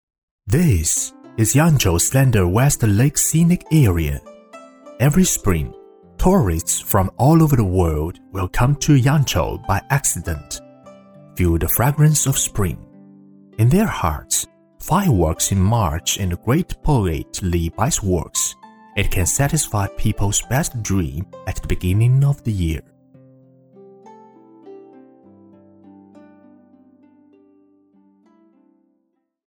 男配音-配音样音免费在线试听-第89页-深度配音网
男679-英文纪录片1-.mp3